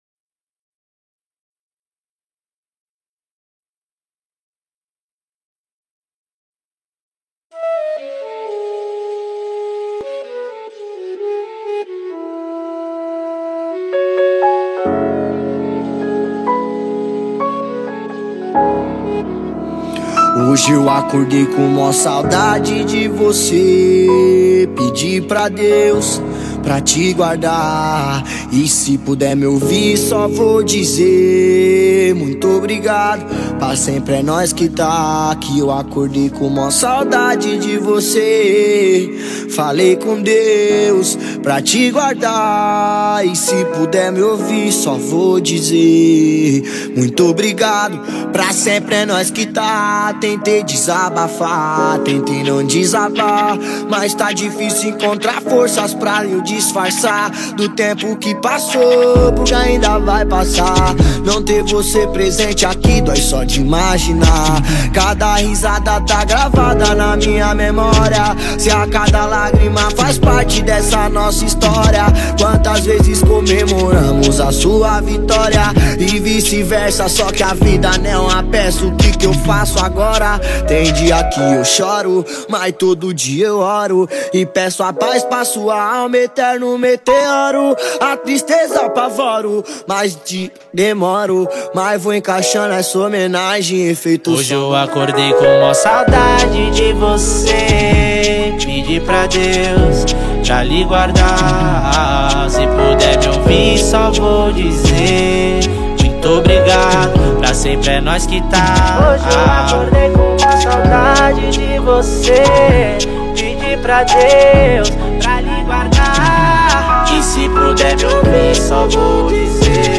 2024-07-25 14:42:49 Gênero: Funk Views